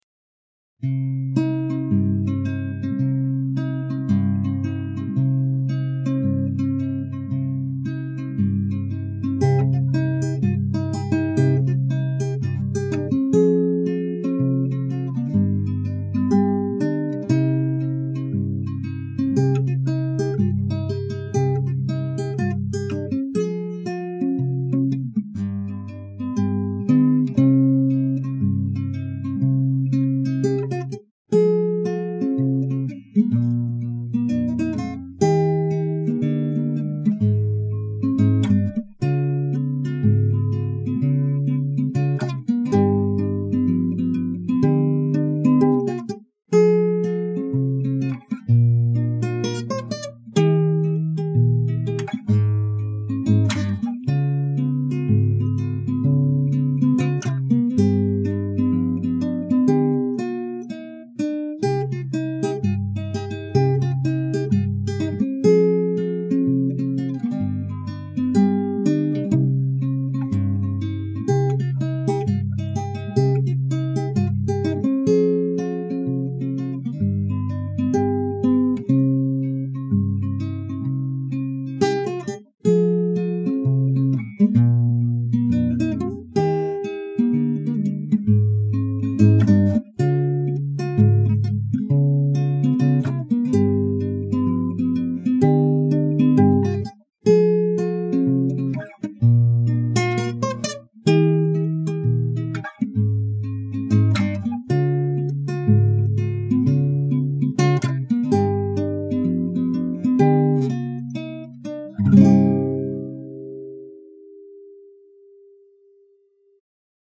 до-минор